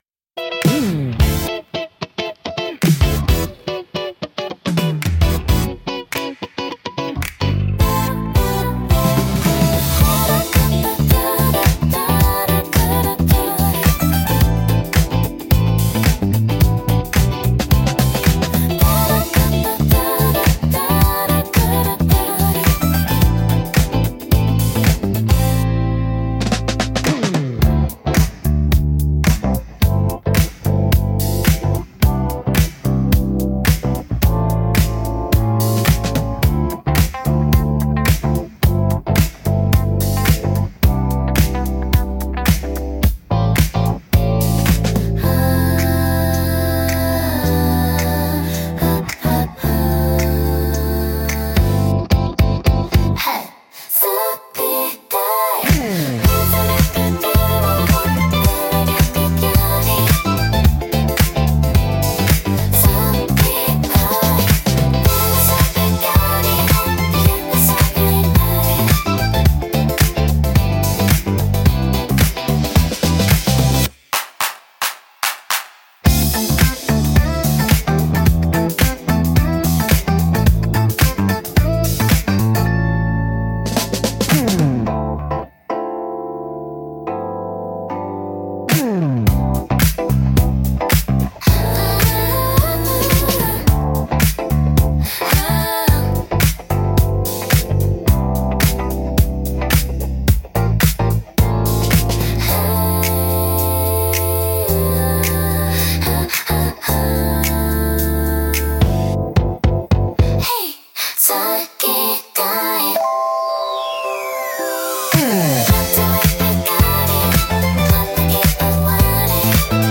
シティポップは、1970～80年代の日本で生まれたポップスの一ジャンルで、都会的で洗練されたサウンドが特徴です。